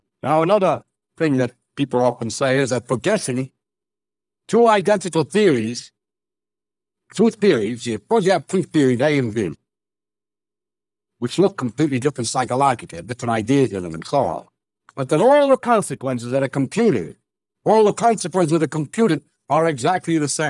audio-denoising audio-to-audio